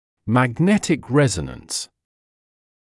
[mæg’netɪk ‘rezənəns][мэг’нэтик ‘рэзэнэнс]магнитный резонанс